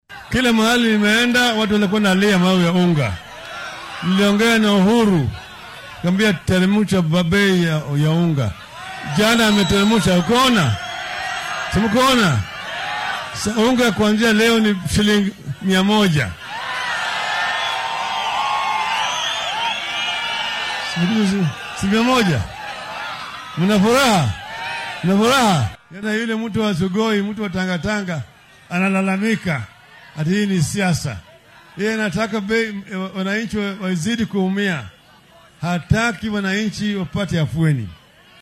Musharraxa madaxweyne ee isbeheysiga Azimio La Umoja-One Kenya, Raila Odinga oo isku soo bax siyaasadeed ku qabtay ismaamulka Nakuru ayaa sheegay inuu madaxweynaha dalka Uhuru Kenyatta la hadlay taasoo horseedday in la yareeyo qiimaha burka.